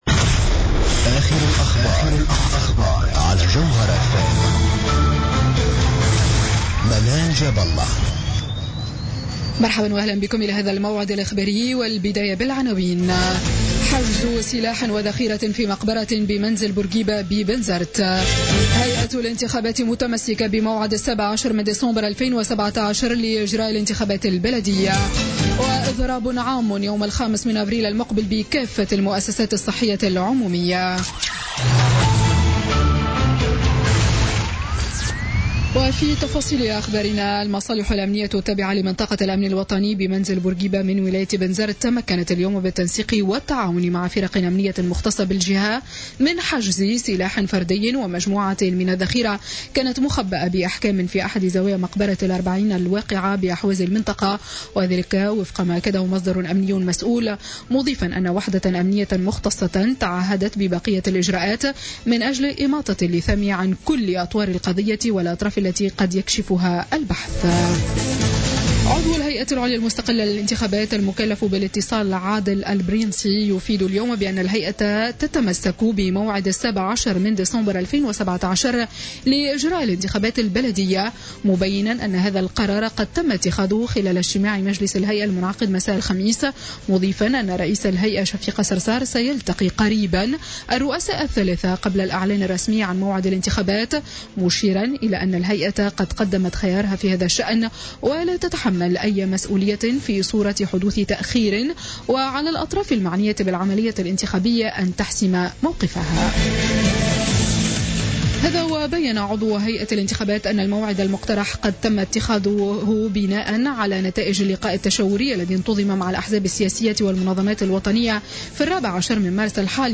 نشرة أخبار السابعة مساء ليوم الجمعة 24 مارس 2017